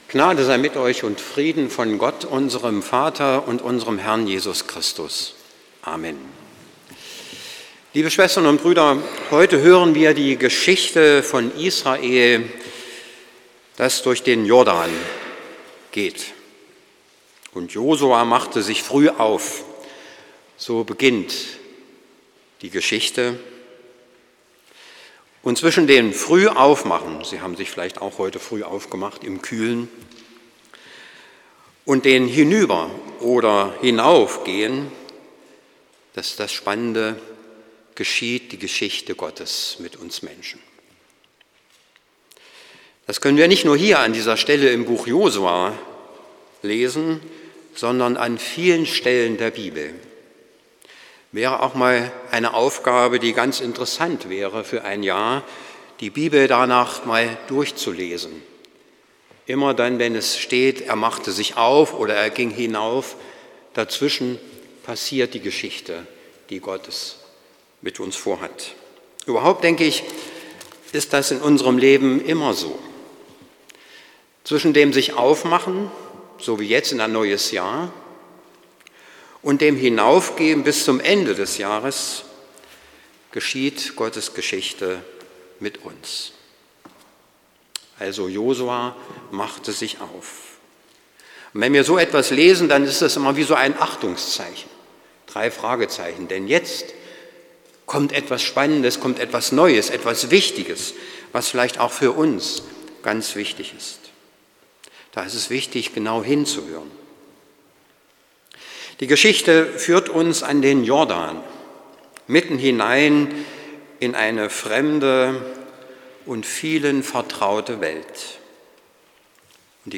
Predigt von Sup.